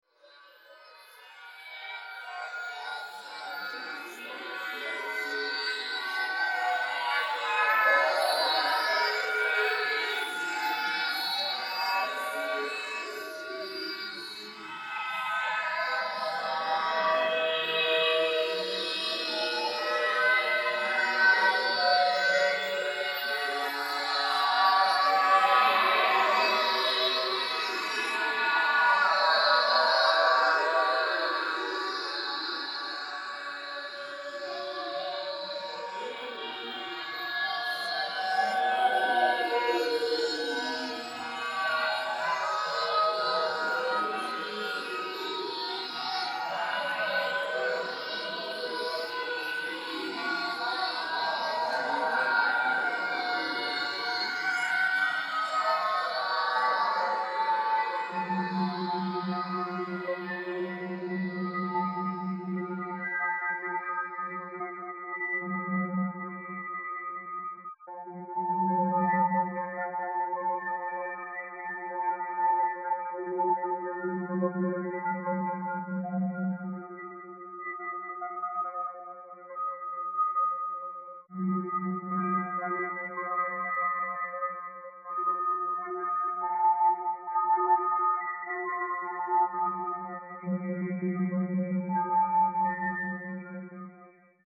Installation sonore dans le cadre de l'exposition "Qui est Andrea Palladio ?".
écouter extrait, partie extérieurere
Composition d'une pièce électroacoustique pour une installation en multiphonie (5.1) pour le Palais de L'Ile d'Annecy, dans le cadre d'une exposition sur l'architecte Italien du 16ème siècle Andrea Palladio. Création le 24 avril, cour et chapelle des Vieilles prisons, musée-château d'Annecy.